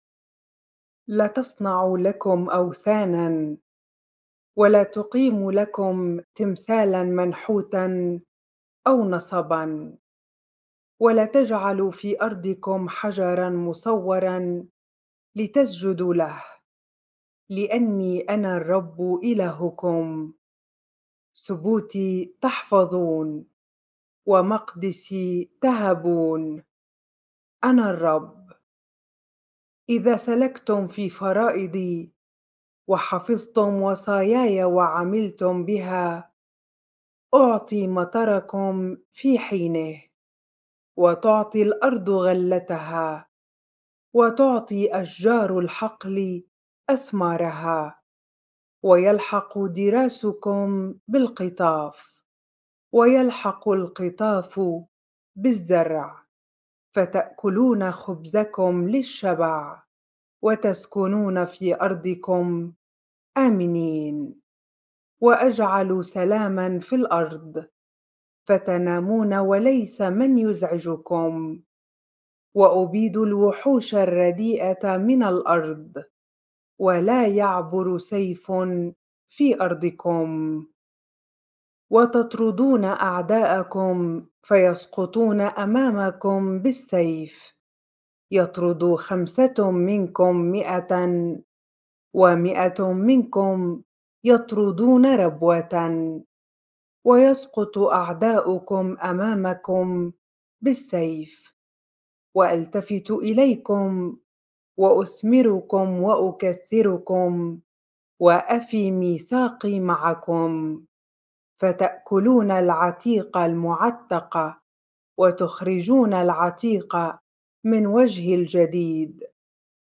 bible-reading-leviticus 26 ar